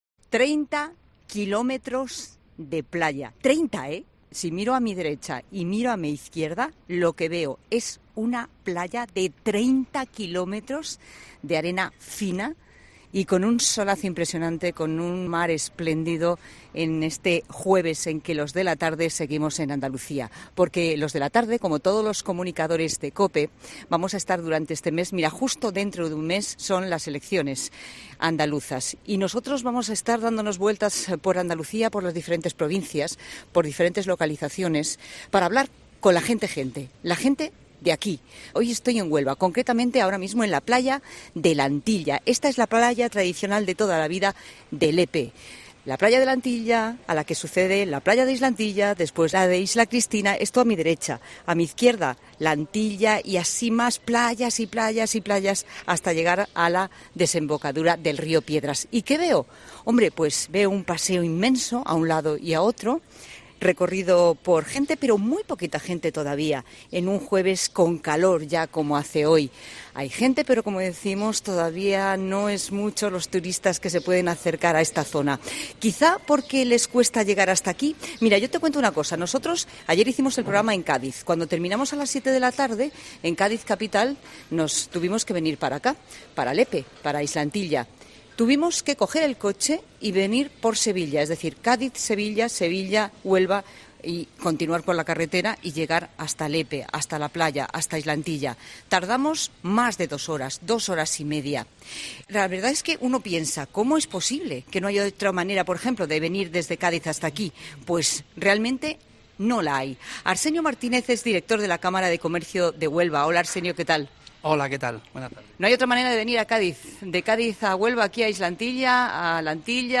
Hoy estoy en la playa de la Antilla, de Lepe.